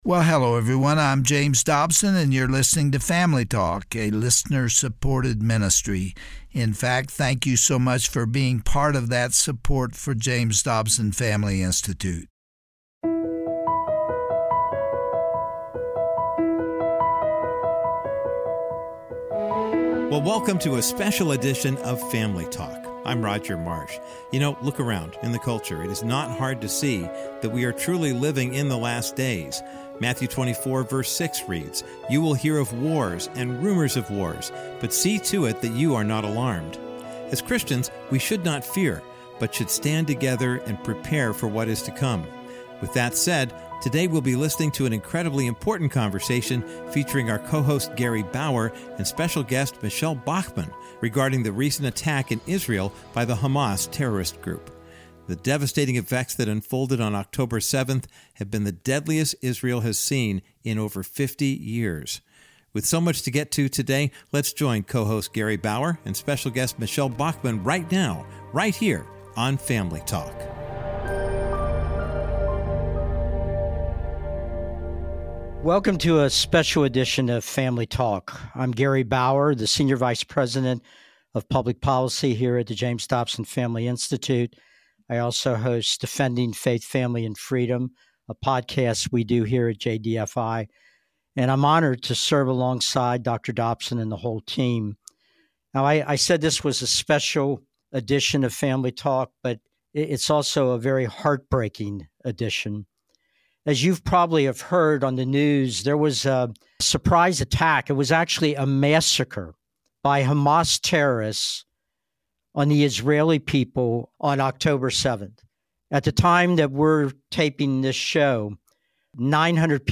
On today’s edition of Family Talk, co-host Gary Bauer is joined by The Hon. Michele Bachmann, former member of the U.S. House of Representatives, to discuss the fallout of these horrific events. Learn more about the history behind these attacks and why we cannot fail to pray for God’s grace and protection over Israel.